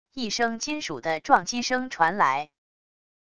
一声金属的撞击声传来wav音频